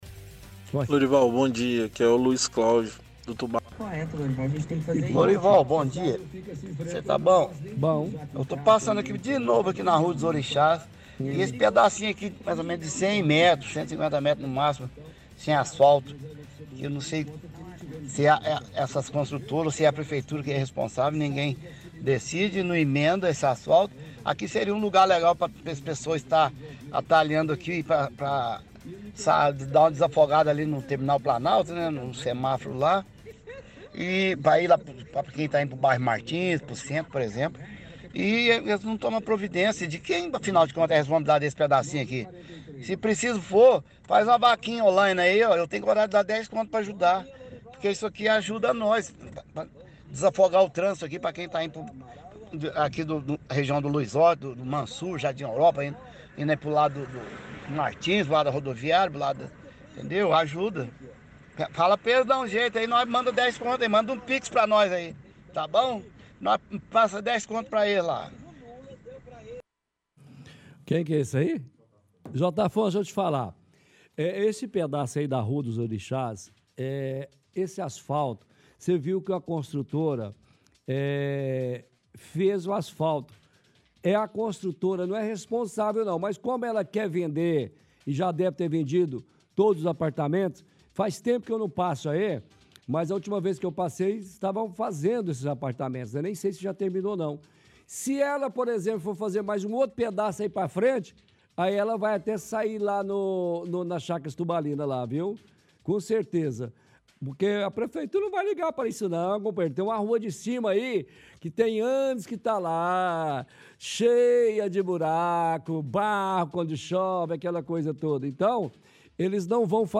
– Ouvinte reclama de falta de asfalto na rua dos Orixás.